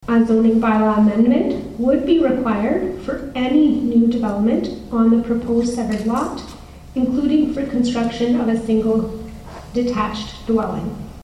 The matter was addressed at a Public Meeting of the Committee at the Nick Smith Centre in Arnprior February 5th.